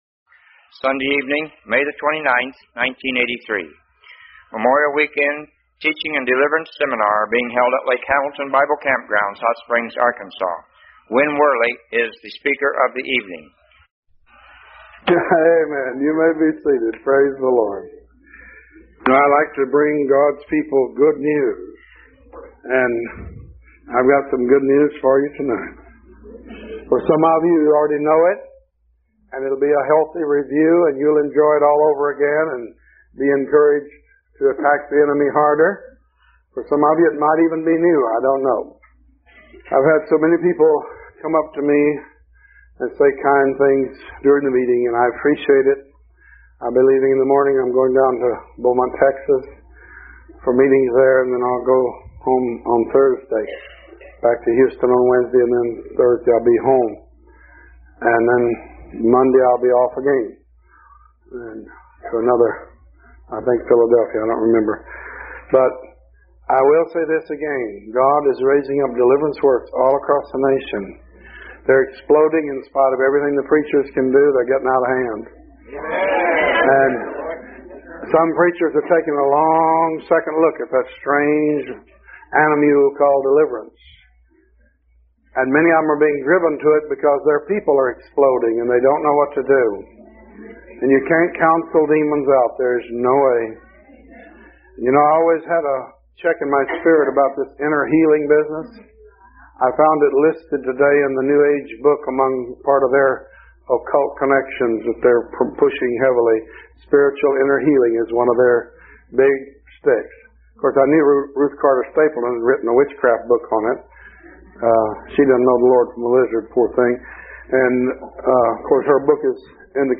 The message was preached at Lake Hamilton Bible Camp in 1983. Despite the sermon's age, it is still the last word on the subject.